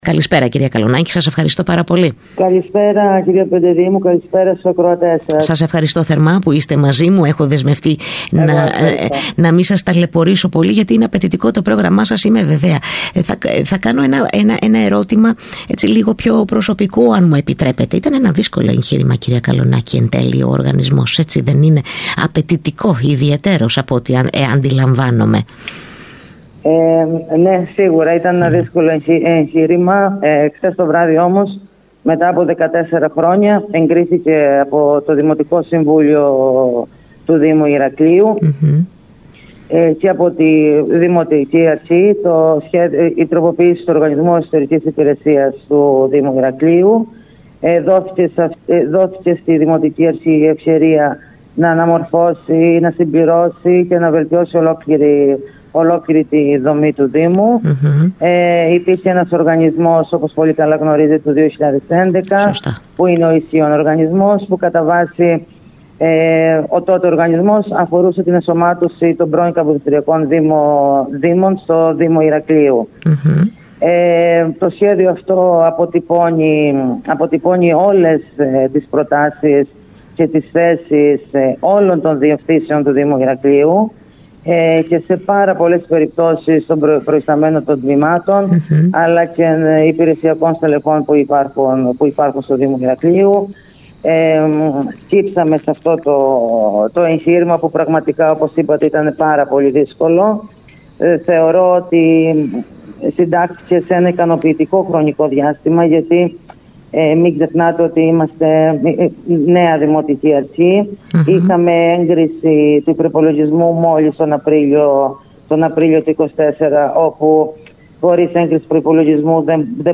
Ακούστε εδώ όσα είπε στον ΣΚΑΙ Κρήτης 92.1 η Αντιδήμαρχος Διοίκησης, Αποκέντρωσης & Ανάπτυξης της Υπαίθρου Γιάννα Καλονάκη: